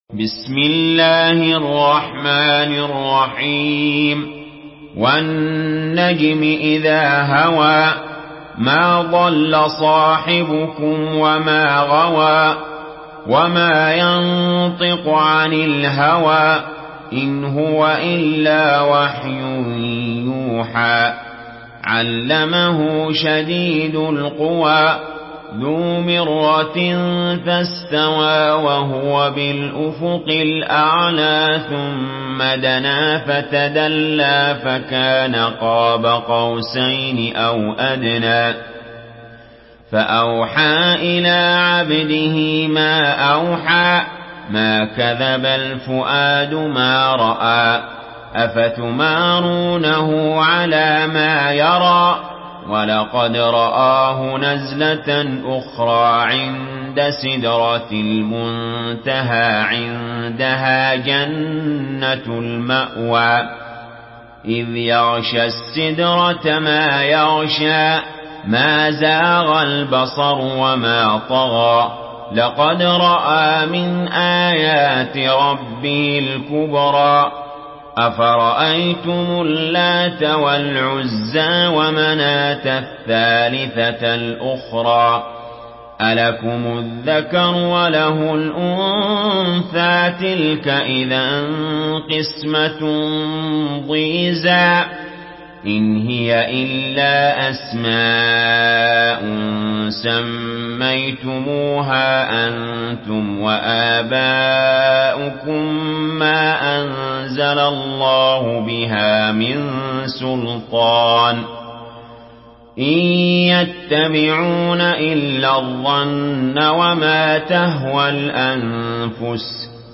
تحميل سورة النجم بصوت علي جابر
مرتل حفص عن عاصم